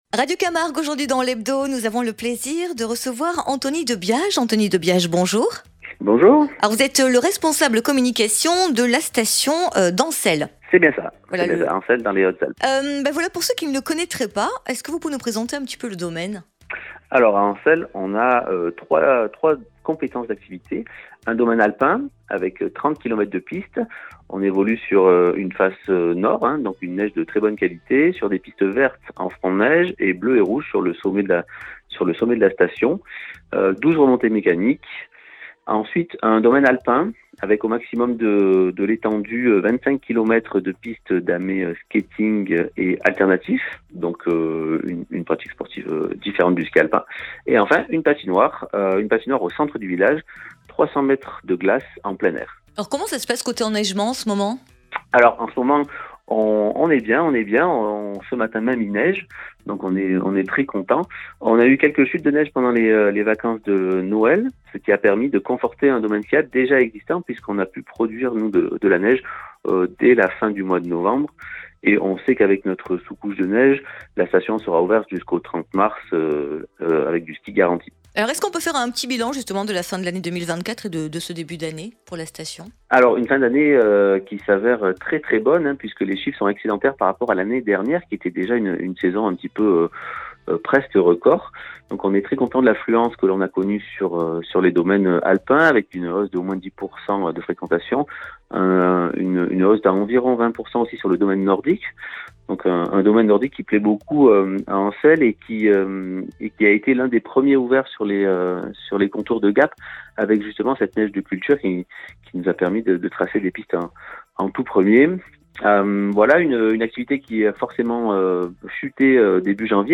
Une immersion dans l’univers d’Ancelle grâce à l'interview exclusive réalisée par Radio Camargue